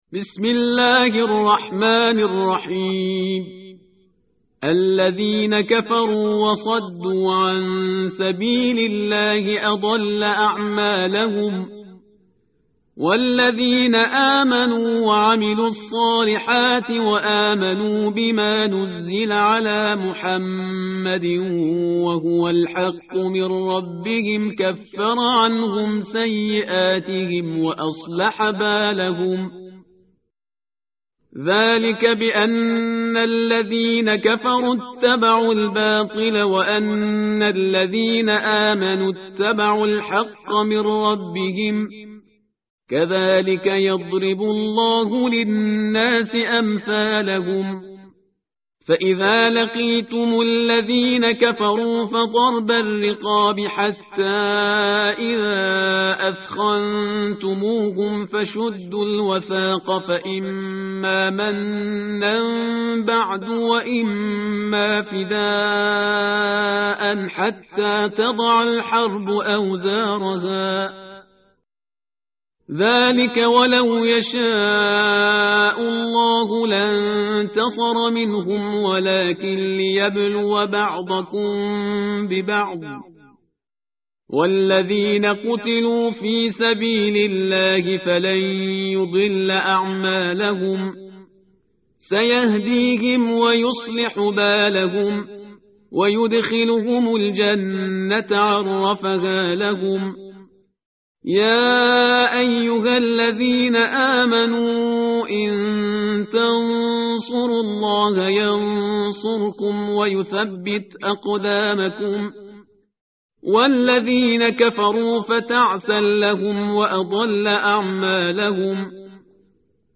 tartil_parhizgar_page_507.mp3